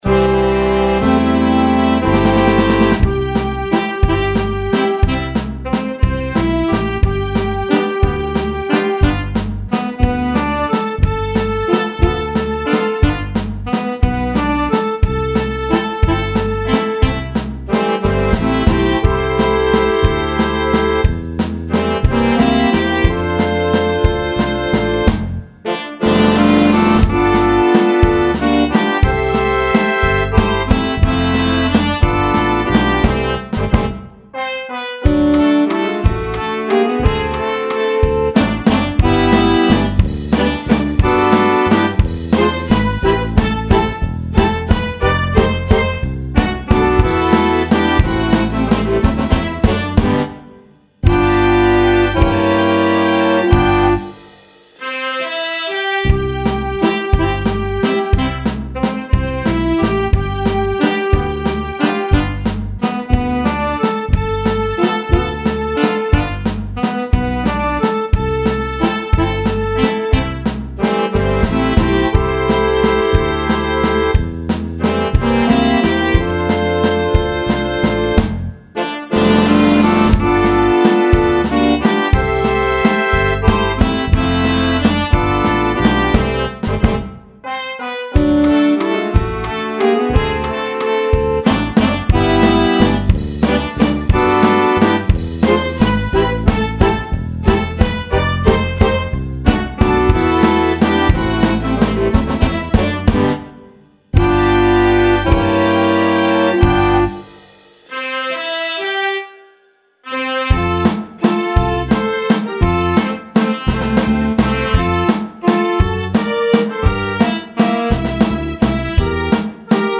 Aranžmá pre malý tanečný band. Úvodný plesový valčík.